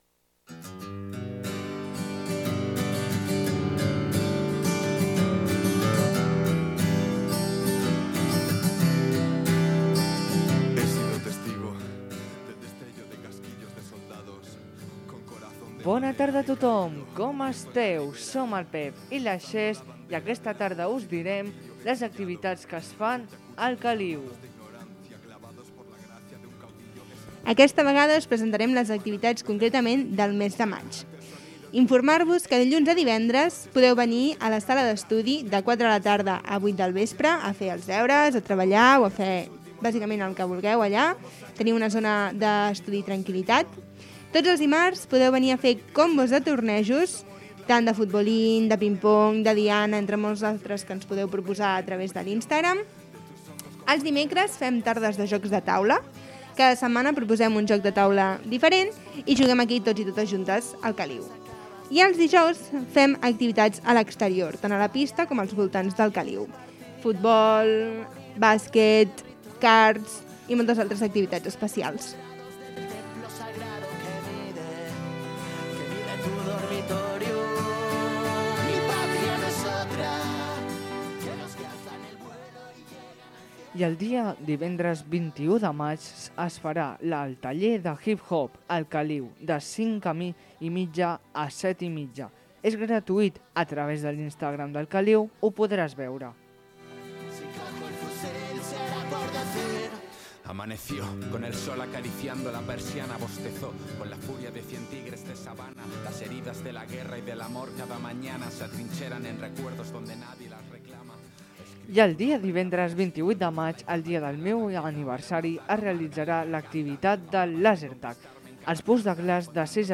20297f40184e590ec1a64ef563b7e4fa3260d6ef.mp3 Títol Ràdio Kaliu Emissora Ràdio Kaliu Titularitat Pública municipal Nom programa Informa Kaliu Descripció Hores d'obertura i activitats del casal de joves El Kaliu de Lliçà de Vall. Gènere radiofònic Informatiu